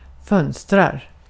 Ääntäminen
IPA: /ˈfœnstɛr/